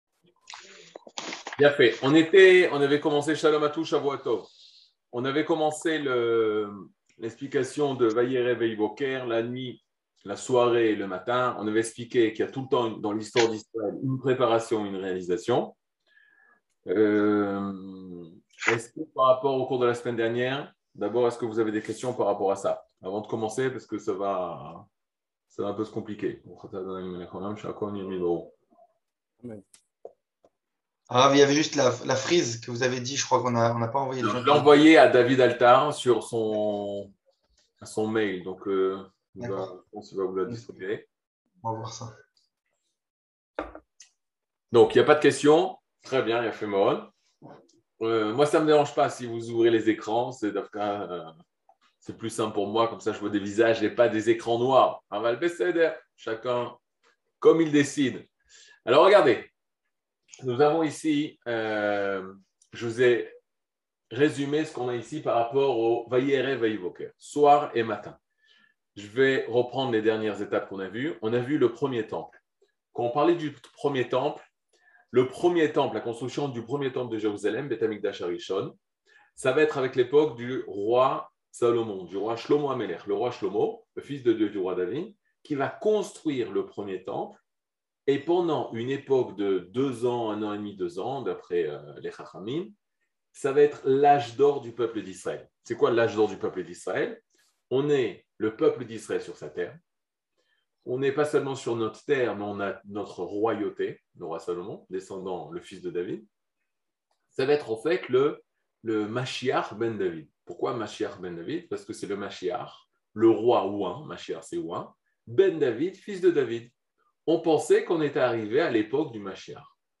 Catégorie Le livre du Kuzari partie 27 00:47:23 Le livre du Kuzari partie 27 cours du 23 mai 2022 47MIN Télécharger AUDIO MP3 (43.38 Mo) Télécharger VIDEO MP4 (128.66 Mo) TAGS : Mini-cours Voir aussi ?